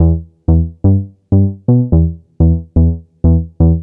cch_bass_loop_pulse_125_Em.wav